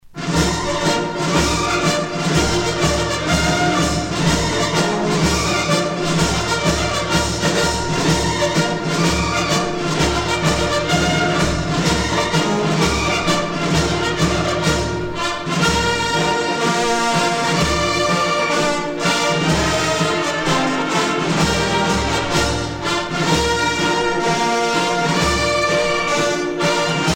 à marcher
militaire
Pièce musicale éditée